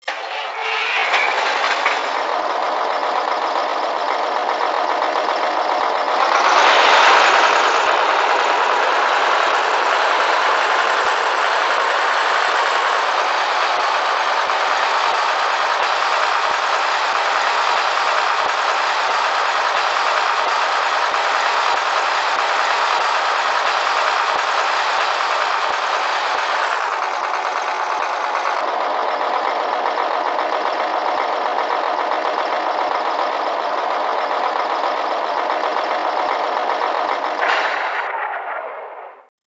• Mit Digital-Decoder mfx+ und voller Geräuschausstattung.
Digitalfunktionen: Fahrsound mit umfangreichen Soundfunktionen, Führerstandsbeleuchtung, Warnblinklicht, dieses Fahrzeug mit mfx Decoder meldet sich an einer mfx fähigen Digitalzentrale selbst an zum Beispiel an der Mobile Station von Märklin, unterstützt das DCC Datenformat